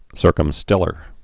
(sûrkəm-stĕlər)